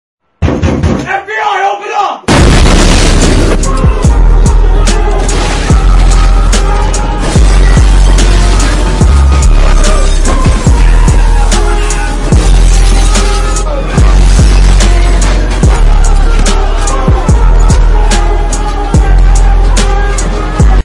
Play, download and share FBI open original sound button!!!!
fbi-open-the-door-1_bby7dl0.mp3